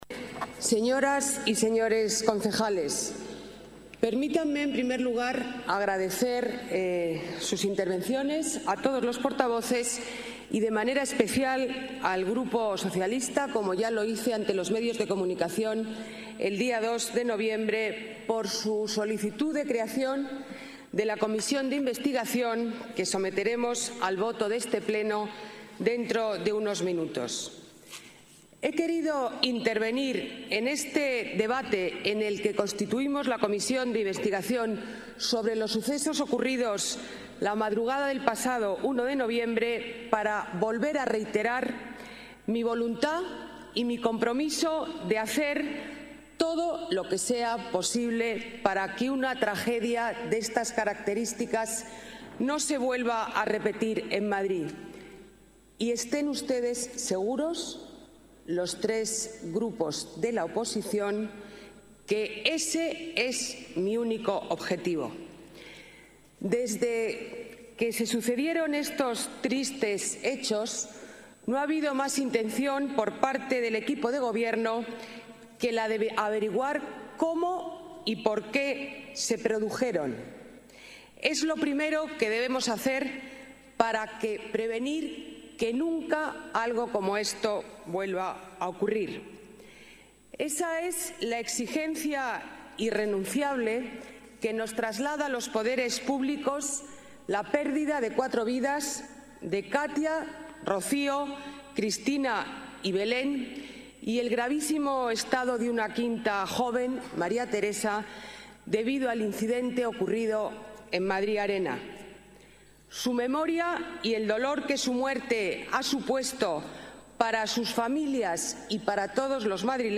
Nueva ventana:Intervención de la alcaldesa, Ana Botella